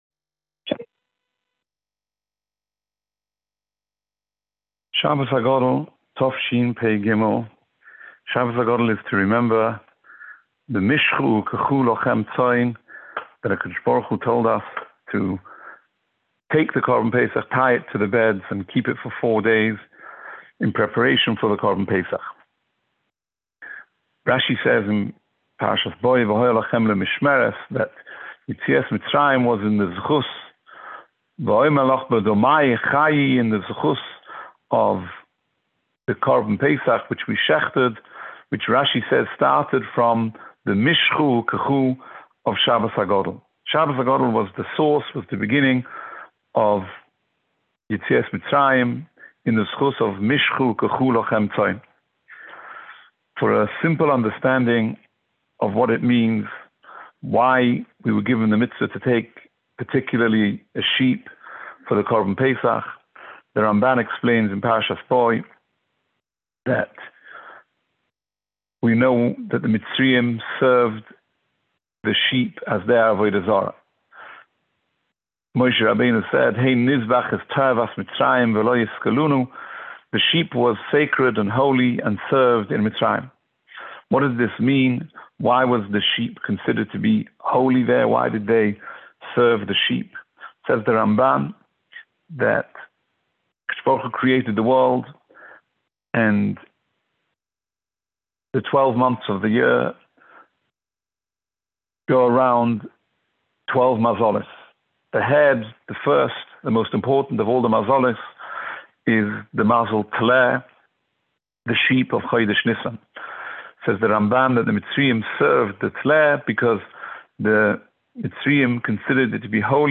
Shiurim